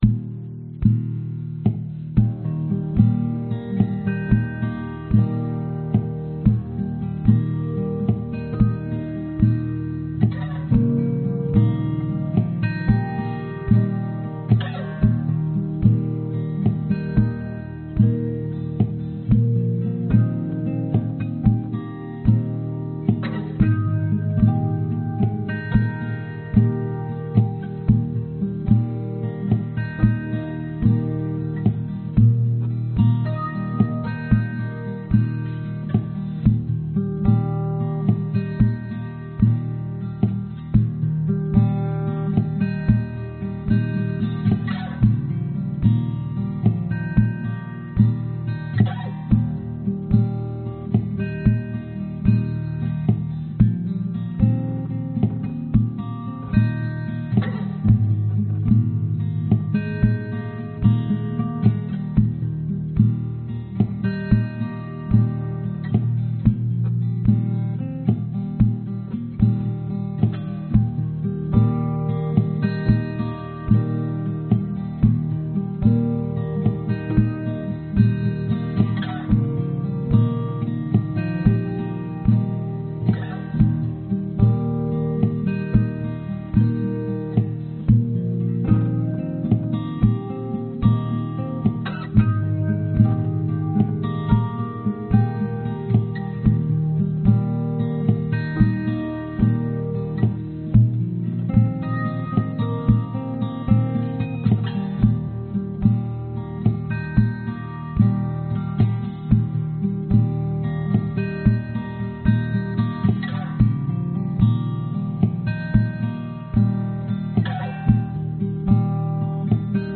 Tag: 原声 寒冷 吉他